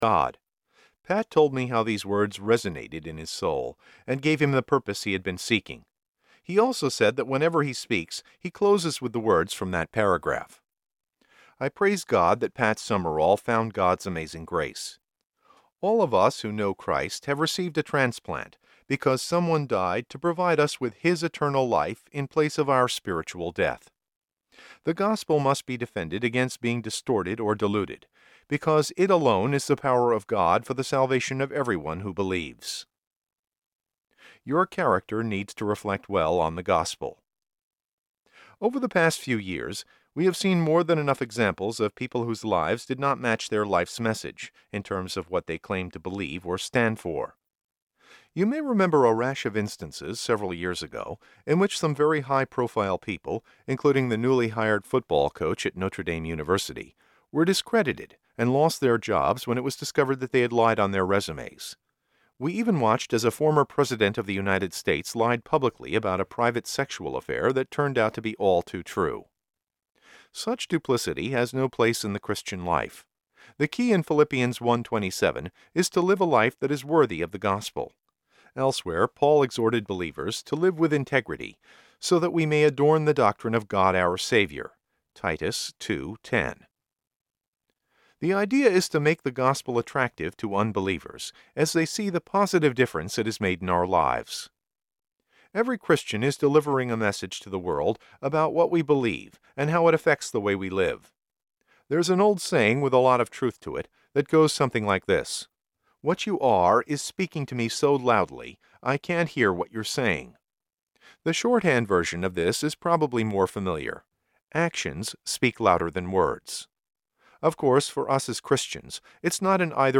Are You Fit For Life? Audiobook
8 Hrs. – Unabridged